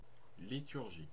Ääntäminen
Synonyymit culte messe cérémonie Ääntäminen France (Normandie): IPA: /li.tyʁ.ʒi/ Haettu sana löytyi näillä lähdekielillä: ranska Käännös Konteksti Substantiivit 1. liturgy uskonto 2. predetermined or prescribed set of 3.